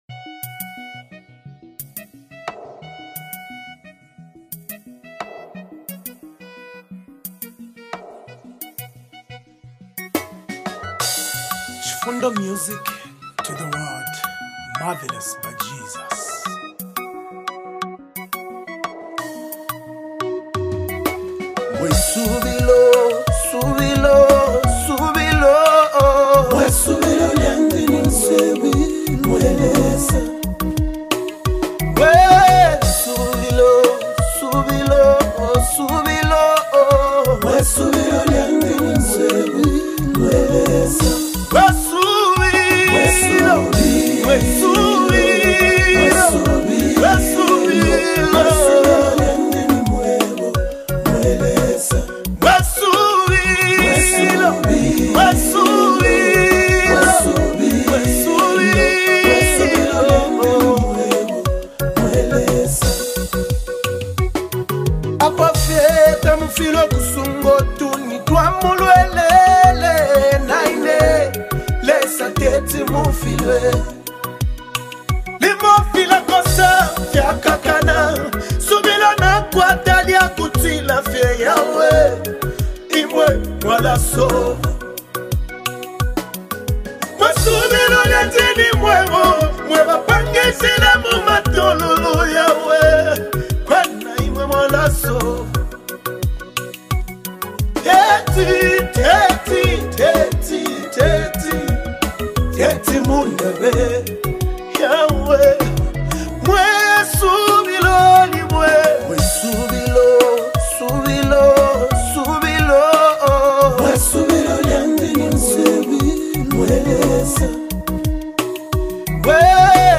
featuring the angelic voice
With its soul-stirring lyrics and melodious harmonies